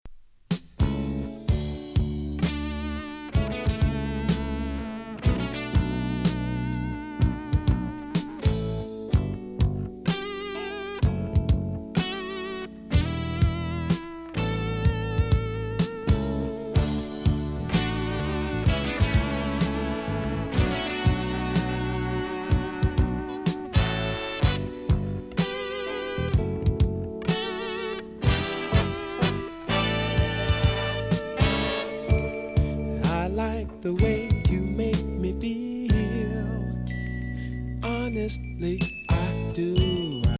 きらめくブラックネスが玉乱極上80’ｓファンク
泣きのファズギターのイントロから骨抜きな胸キュン・バラード